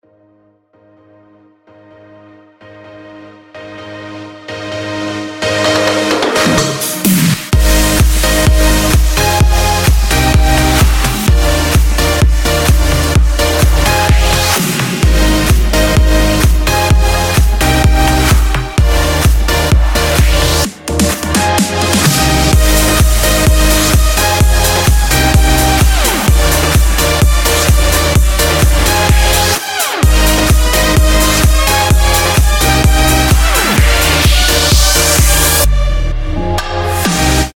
Сведение Prog/House